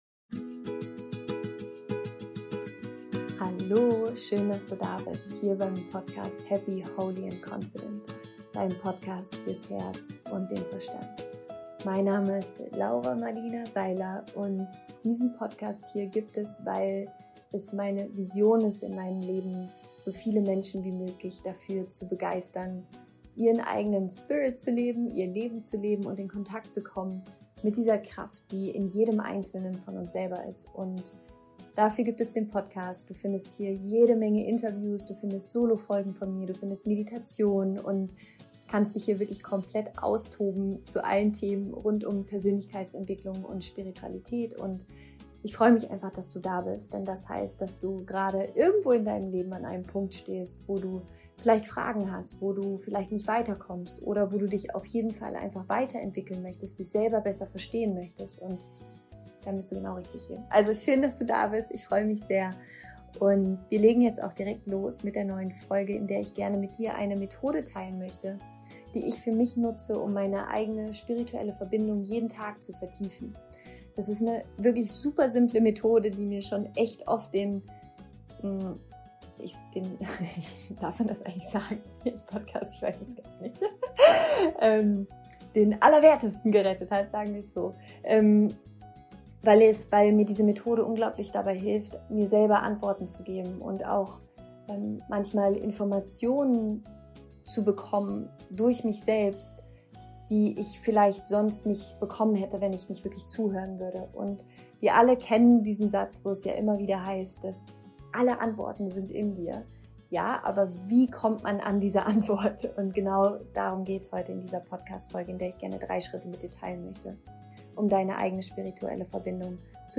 Ich gehe mit dir die 3 Schritte dieser Methode durch und leite sie dann auch in einer Meditation für dich an.